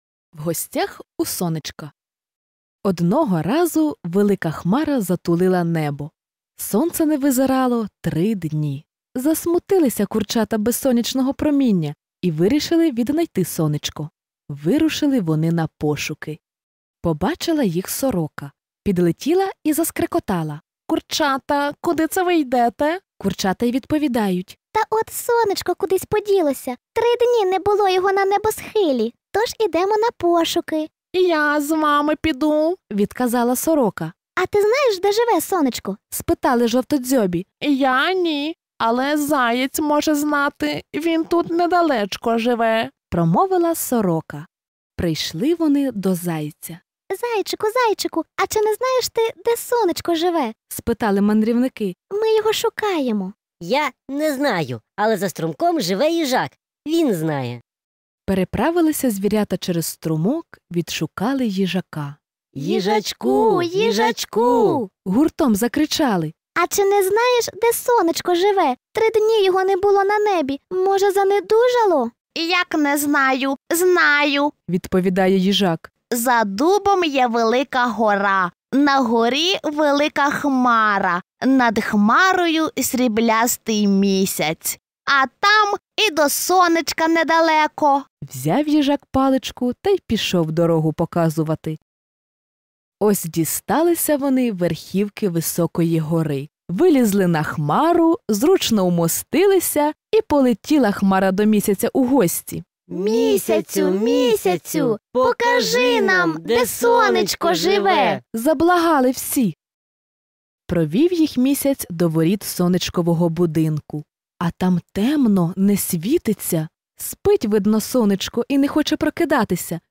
Аудіоказка “В гостях у сонечка” українською – слухати та скачати безкоштовно в форматах MP3 і M4A
Аудіоказки для маленьких діточок: слухати і скачати